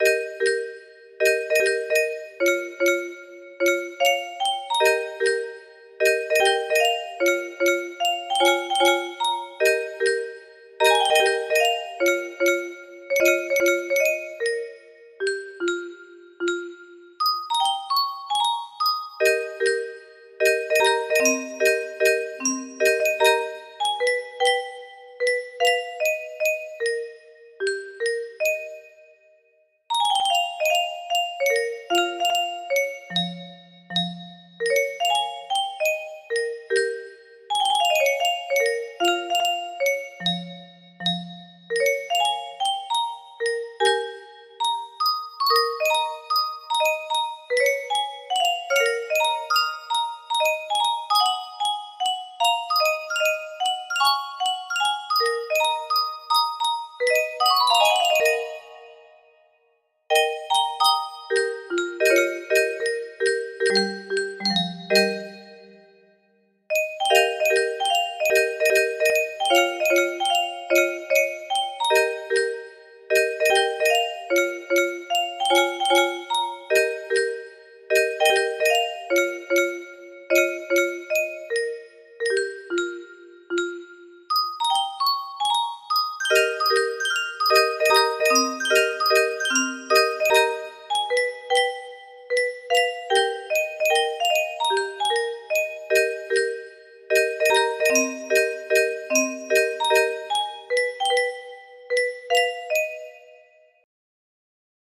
Grand Illusions 30 (F scale)
I just had to make this in music box form.